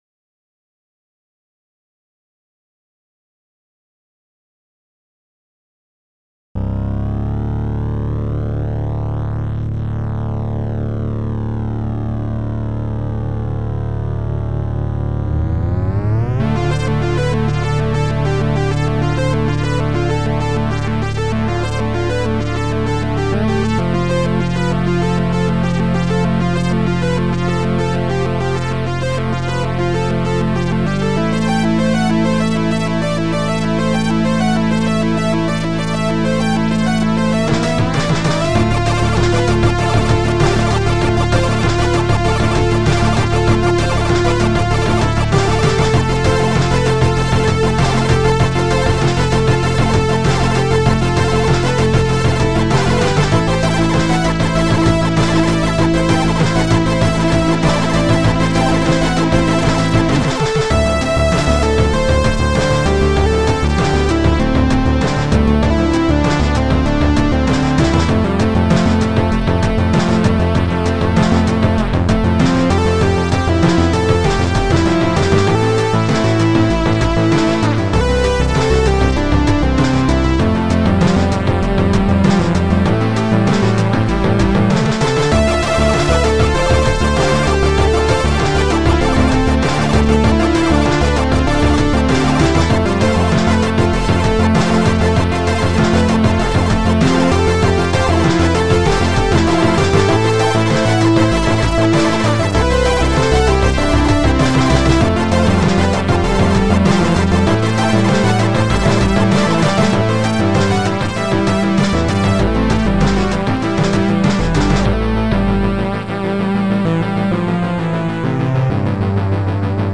“chiptune” track